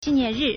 纪念日 (紀念日) jìniànrì
ji4nian4ri4.mp3